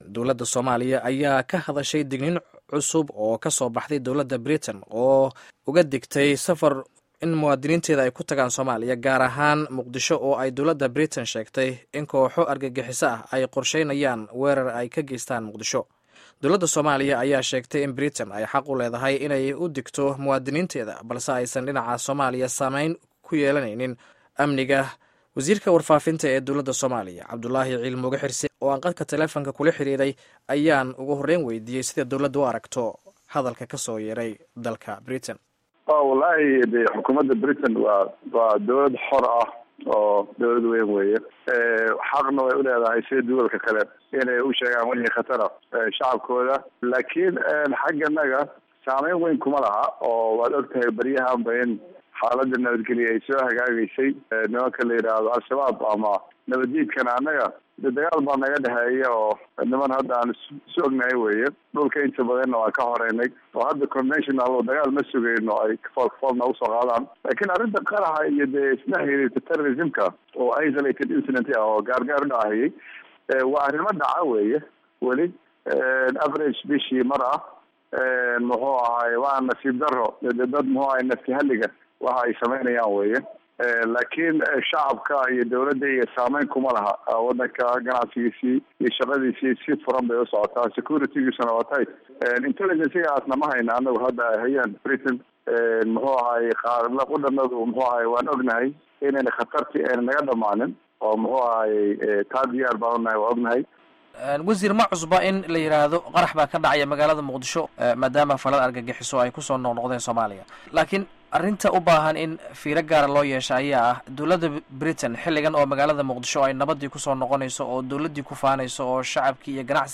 Dhageyso wareysiga Wasiirka Warfaafinta